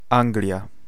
Ääntäminen
Vaihtoehtoiset kirjoitusmuodot (vanhahtava) Ingland Ääntäminen UK US UK : IPA : /ˈɪŋɡlənd/ US : IPA : /ˈɪŋɡlənd/ GenAm: IPA : /ˈɪŋɡlɪ̈nd/ Haettu sana löytyi näillä lähdekielillä: englanti Käännös Ääninäyte Erisnimet 1.